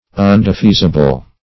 \Un`de*fea"si*ble\